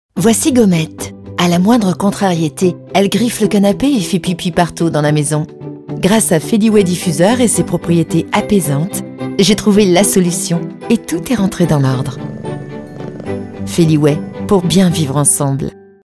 féminie // apaisante
Feliway-féminie-apaisante.mp3